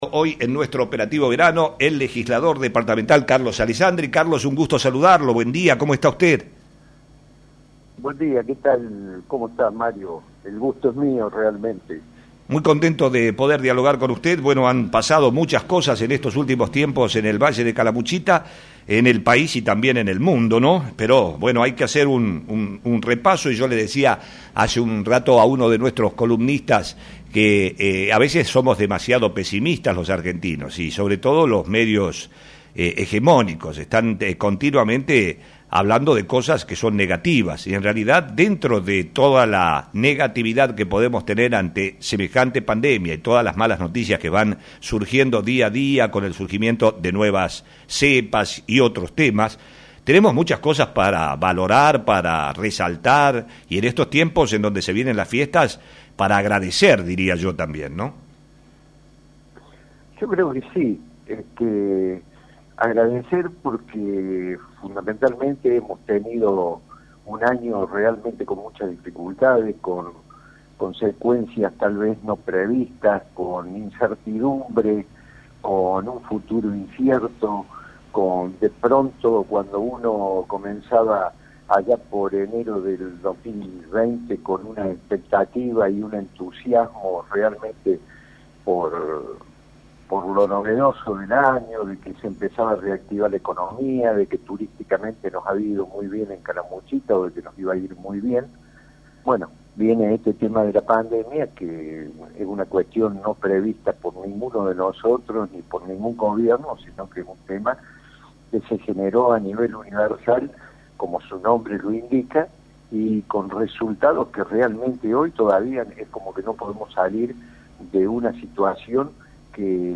En una extensa entrevista el Legislador Departamental habló de la forma en que afectó la pandemia al valle y destacó la labor de todo el personal de salud de la región. Luego de hacer un repaso por las obras que se están ejecutando en todo el valle, Alesandri se refirió a los objetivos que se deben trazar para el futuro y los anuncios hechos por Matías lammens y Martín Gill para la puesta en marcha de obras en la Unidad Turística y el Polideportivo de Embalse.